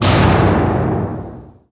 thud3.wav